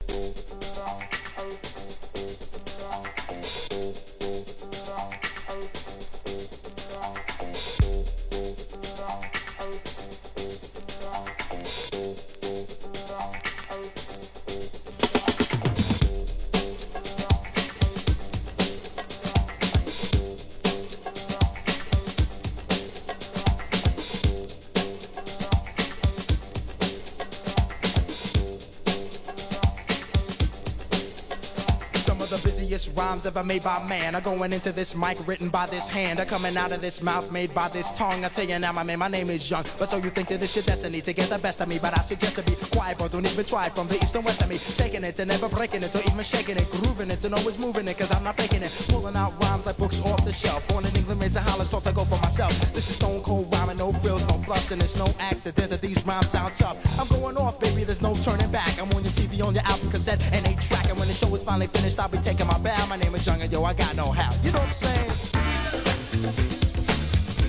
HIP HOP/R&B
随所にFAST RAPPIN'でスキルもしっかり披露!!